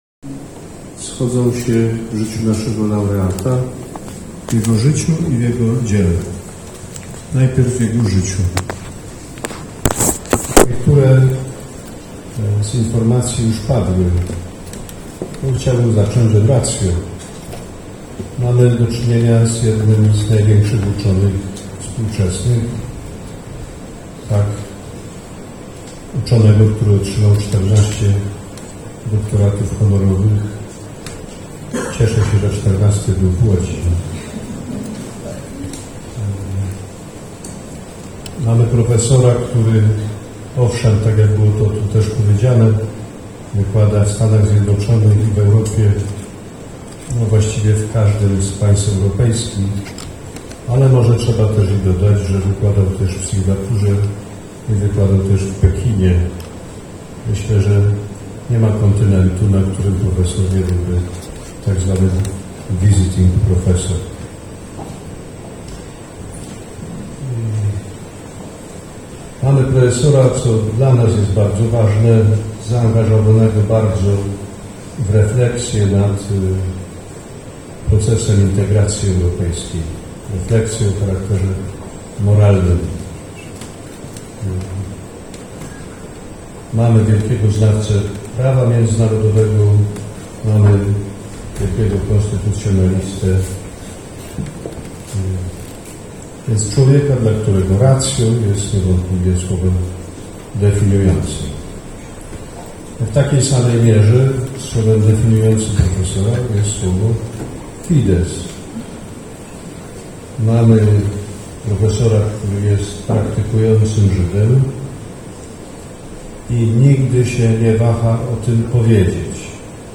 Profesora Weilera definiują oba aspekty – fides i ratio. To największy współczesny uczony, profesor zaangażowany w refleksje moralne nad procesem integracji europejskiej, wielki znawca prawa międzynarodowego, wybitny konstytucjonalista, a jednocześnie jest także praktykującym żydem, mówiącym publicznie o swojej wierze, mający głęboką wrażliwość na wiarę innych osób – mówił w swoim wystąpieniu kard. Grzegorz Ryś, arcybiskup metropolita łódzki, pierwszy laureat Nagrody Fides et Ratio.
Wystąpienie audio:
kardynalgrzegorz_rys.mp3